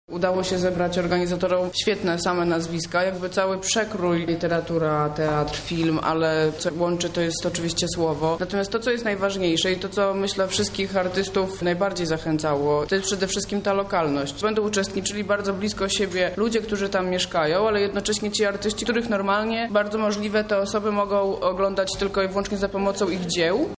Jestem zaszczycona, że mogę uczestniczyć w tym projekcie – mówi Katarzyna Bonda, pisarka.